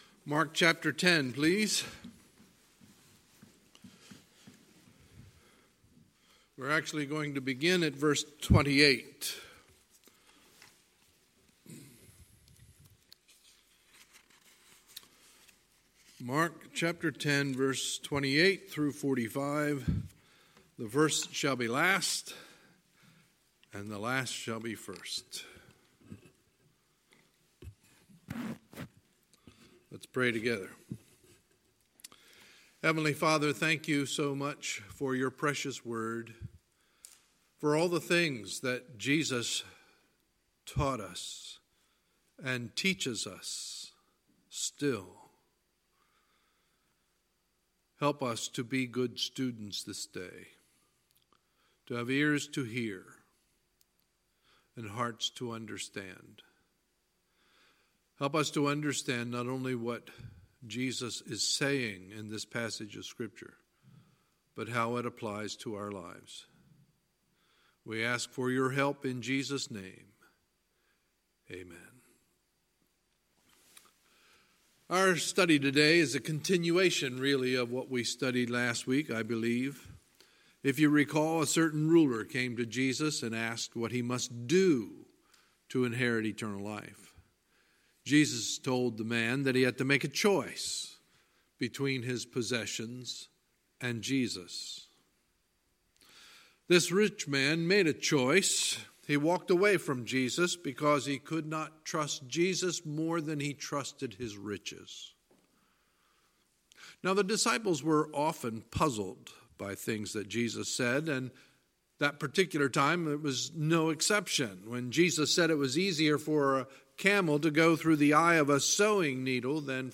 Sunday, August 25, 2019 – Sunday Morning Service